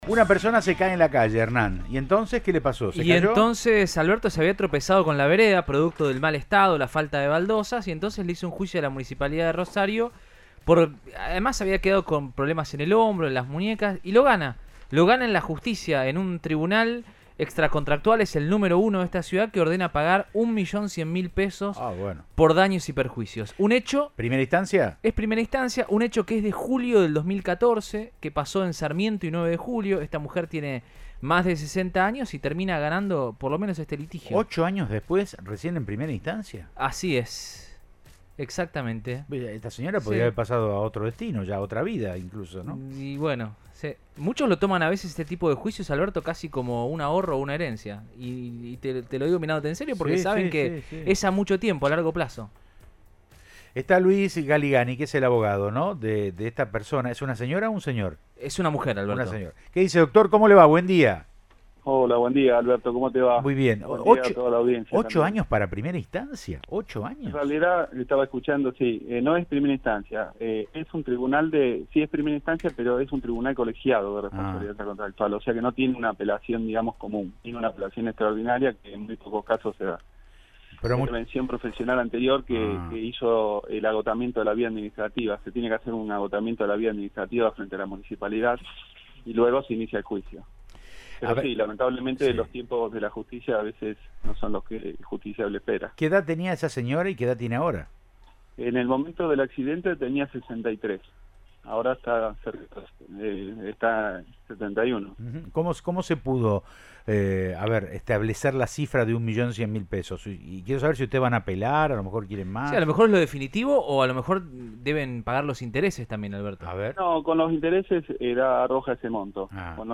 dialogó con Siempre Juntos de Cadena 3 Rosario y brindó detalles del caso.